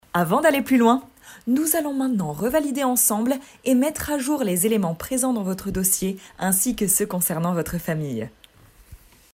Extrait voix film d'entreprise